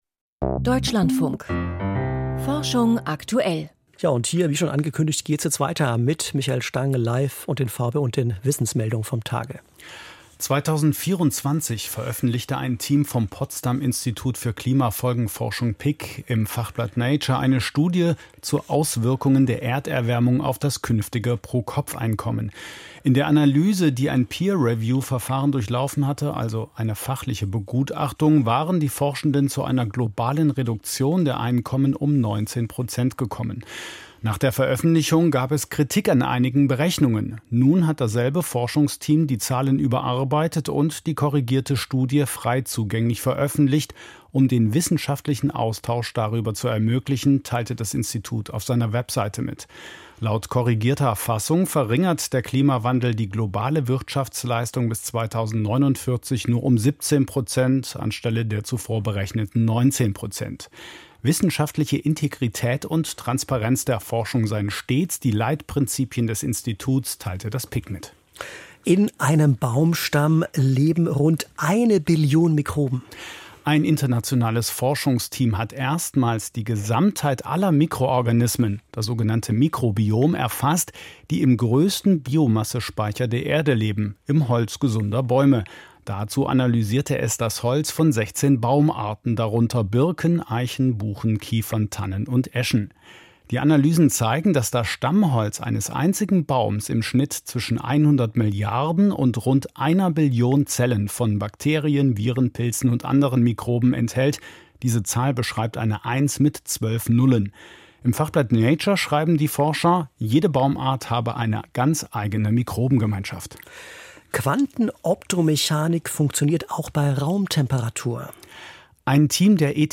Wissenschaftsmeldungen 20.12.2024. Schneller zum Einsatzort: Software für schnelleres Bilden von Rettungsgassen.